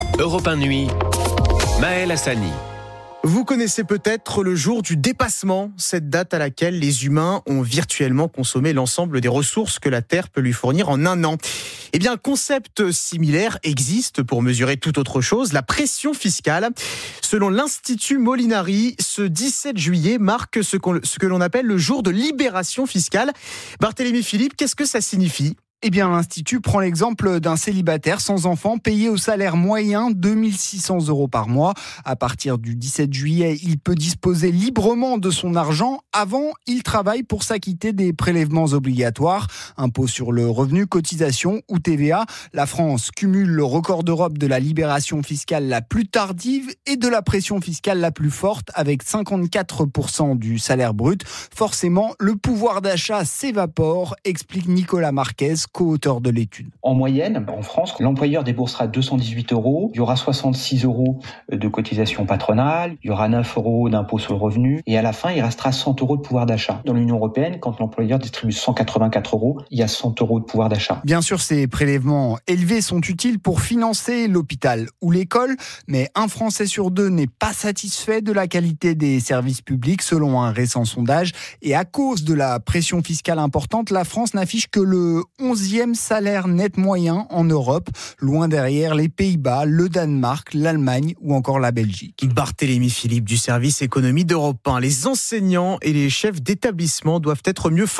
Europe1 nuit, 17 juillet 2024, 22h09